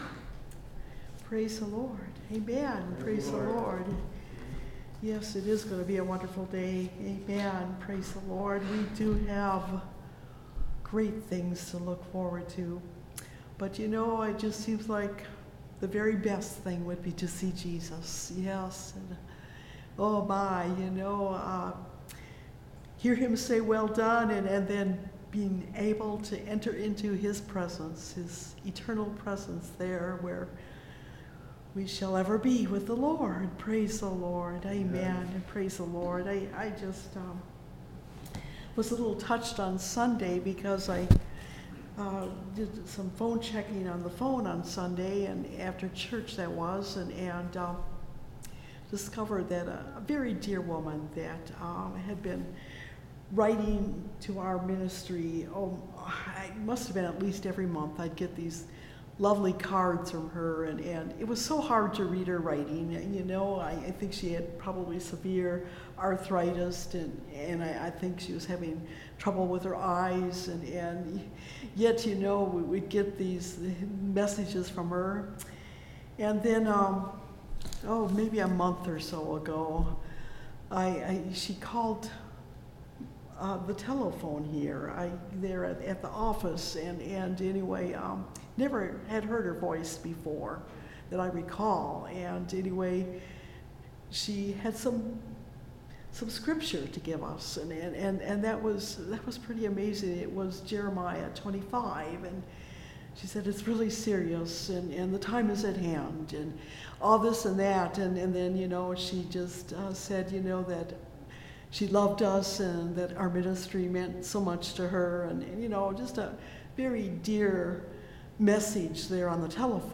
Great And Sore Troubles (Message Audio) – Last Trumpet Ministries – Truth Tabernacle – Sermon Library